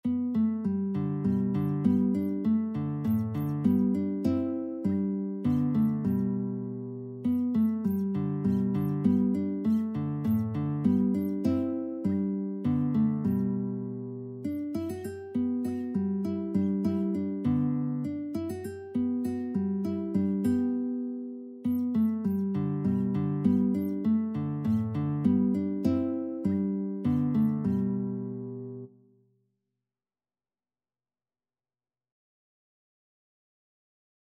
"Come Thou Fount of Every Blessing" is a Christian hymn written by the 18th century pastor and hymnist Robert Robinson.
G major (Sounding Pitch) (View more G major Music for Guitar )
3/4 (View more 3/4 Music)
Guitar  (View more Easy Guitar Music)
Classical (View more Classical Guitar Music)